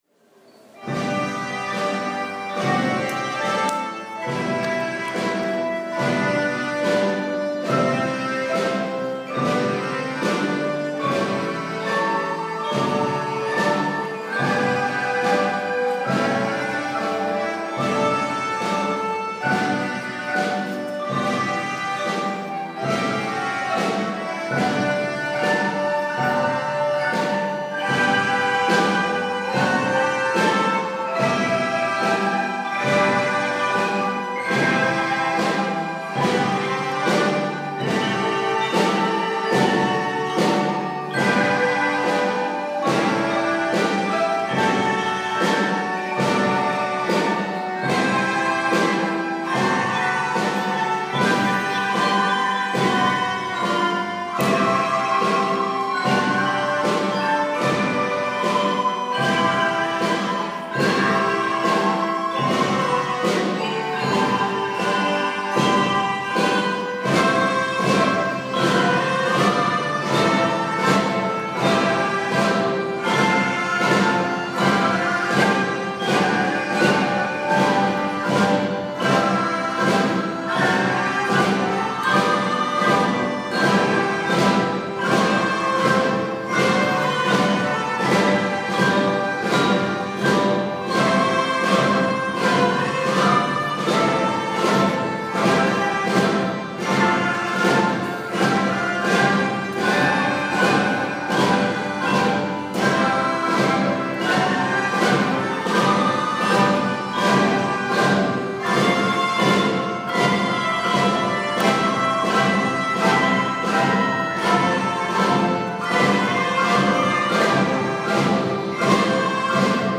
４月からリコーダーの学習を始めたばかりの３年生。
この大空創立記念コンサートでは３年生がリコーダー、４年生が打楽器や鍵盤ハーモニカやオルガンなどを担当します。
合奏が始まると、３年生がリコーダーで優しい音色を奏で、４年生がゆったりと堂々とした演奏で３年生をリードし、まさに“威風堂々”とした演奏をみんなでつくりました♪